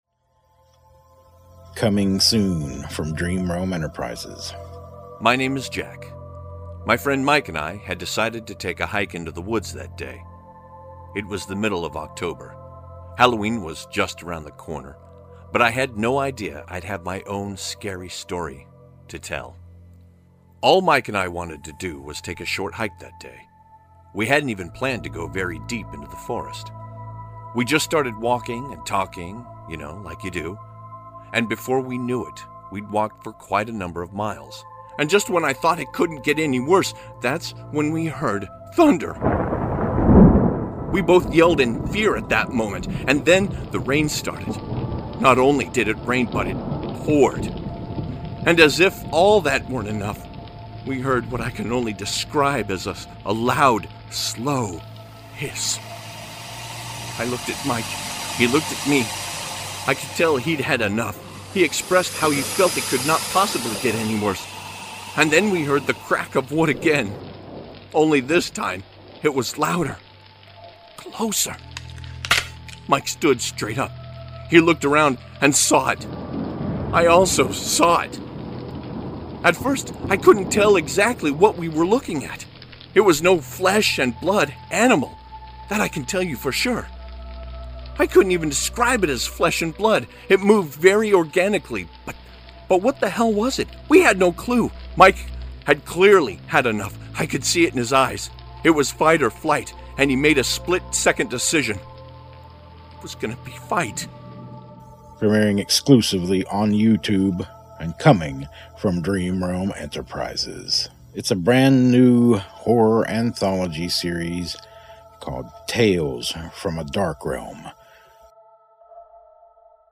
Some stories will be full cast dramas, while others will be prose read like an audiobook.